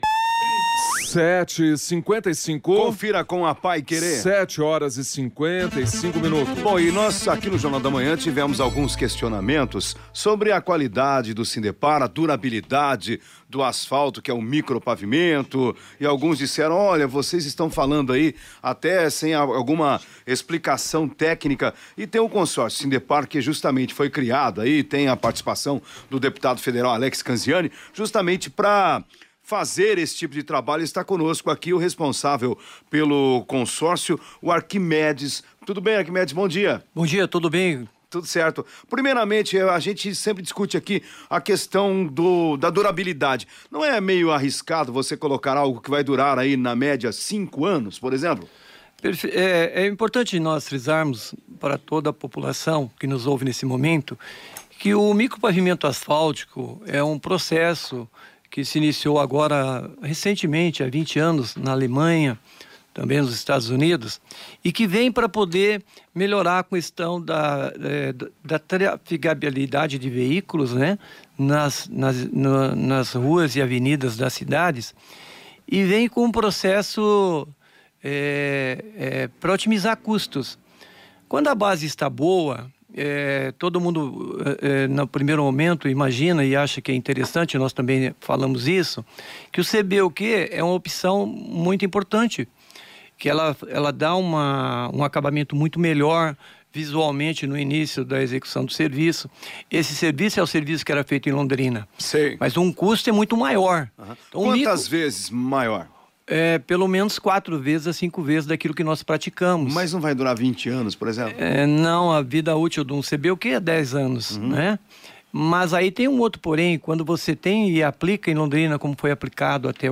que hoje de manhã concedeu uma entrevista de 10 minutos, ao vivo, no estúdio da Rádio Paiquerê AM de Londrina.